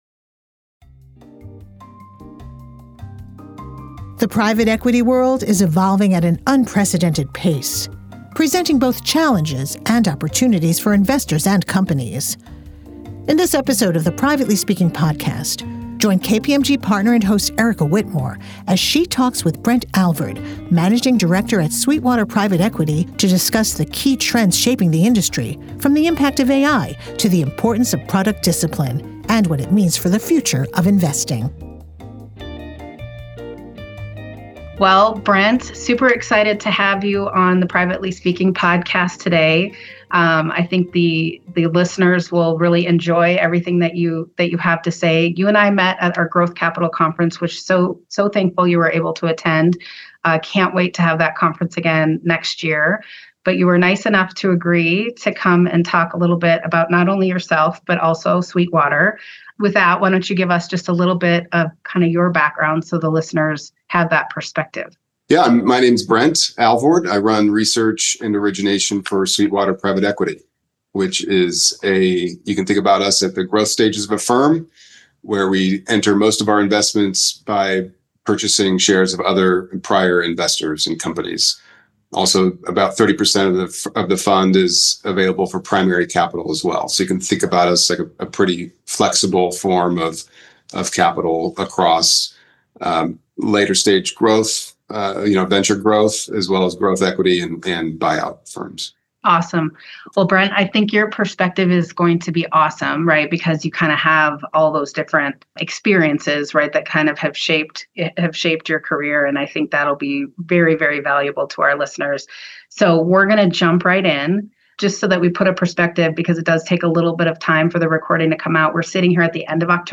Prepare for a conversation packed with bold ideas and actionable insights that challenge conventional thinking about the future of private equity.